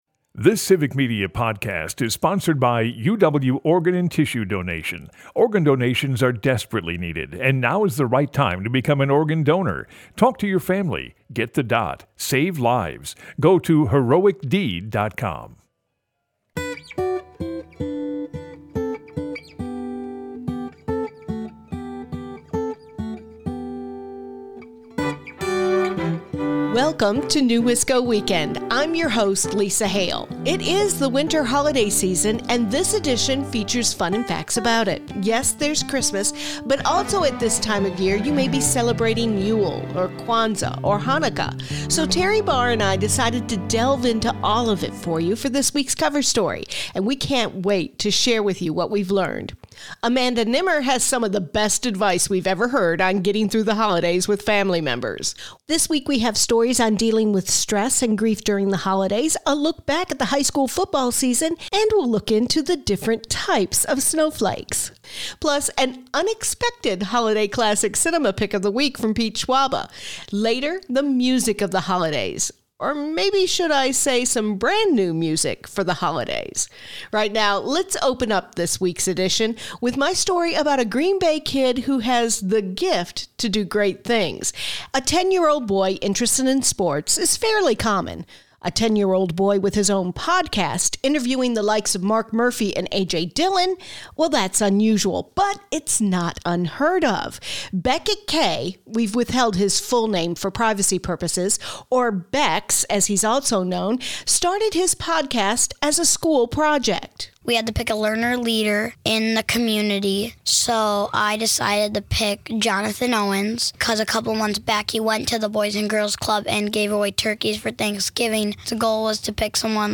It's Friday and that means two things on Maino & The Mayor: Football and LIVE Music. FOOTBALL FRIDAY with Burkel's One Block Over features former Green Bay Packer Matt Brock! Matt played defensive end for eight seasons in the NFL.